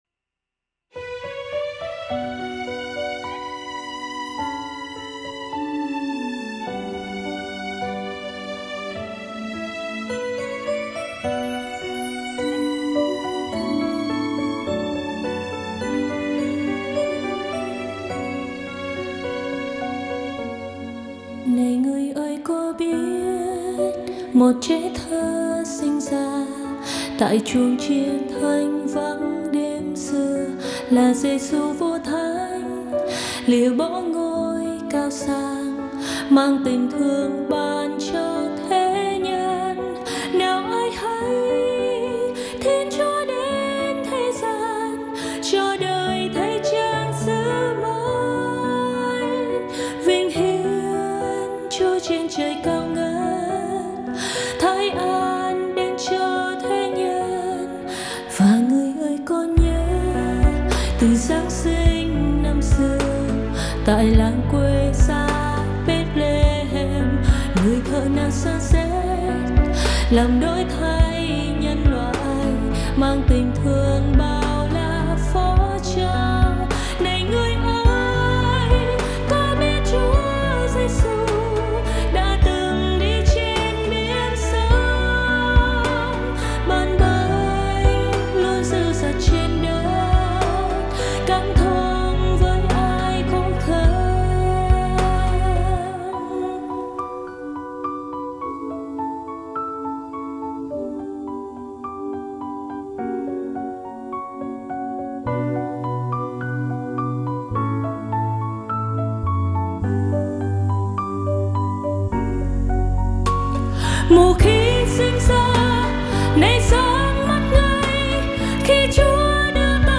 Bài hát Truyền giảng: VÌ SAO CHÚA ĐẾN